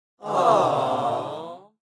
Crowd - Awww